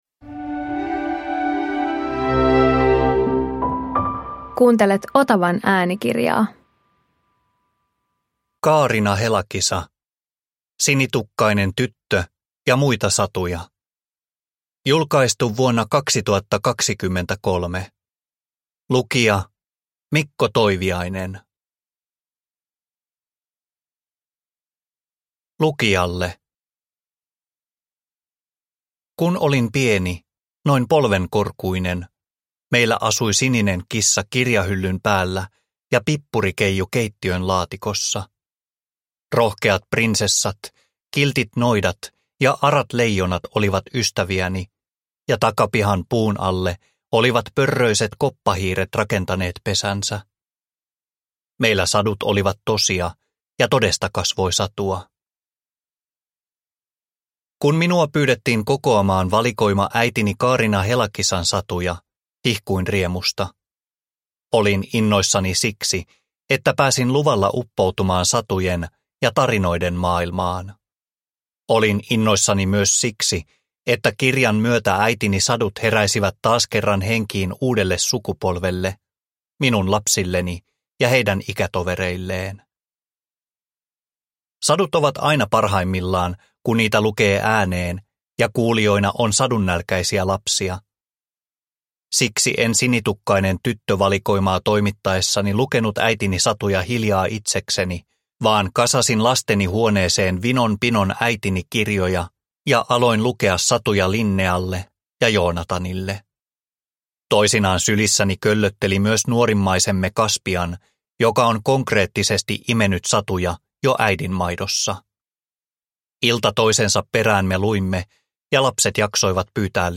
Sinitukkainen tyttö ja muita satuja – Ljudbok